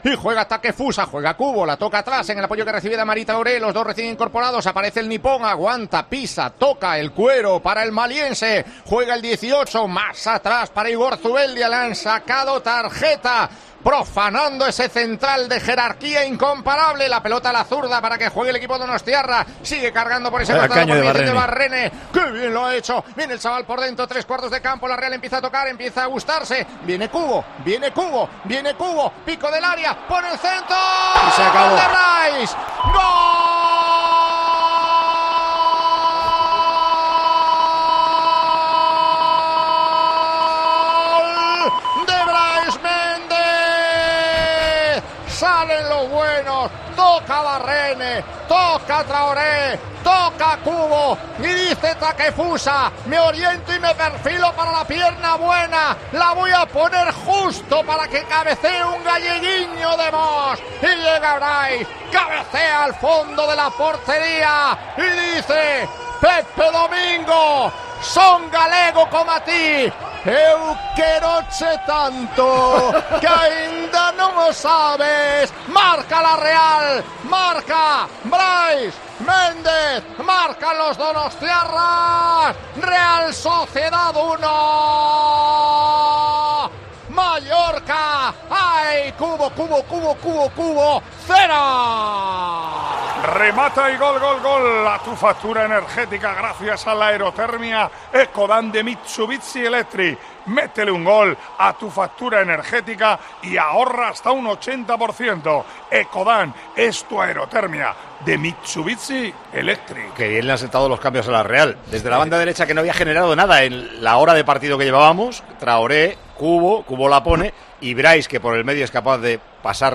Micrófono de COPE en Anoeta Reale Arena